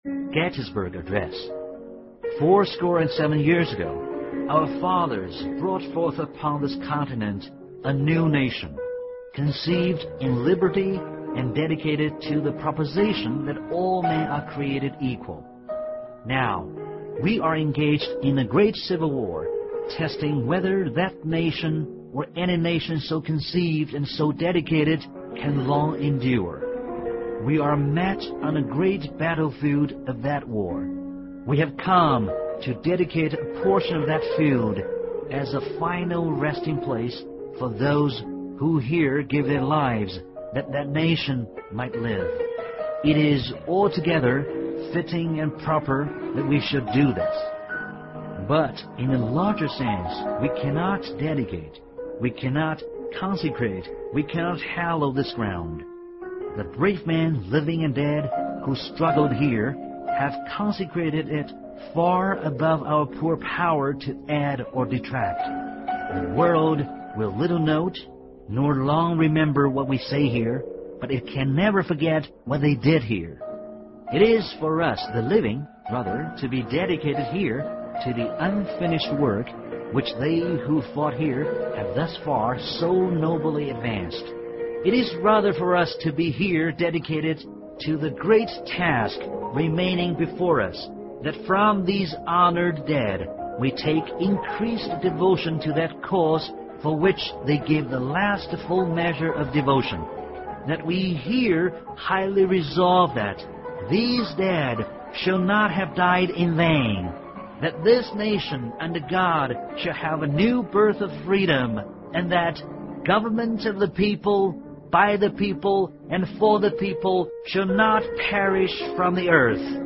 双语有声阅读：林肯在葛底斯堡的演说 听力文件下载—在线英语听力室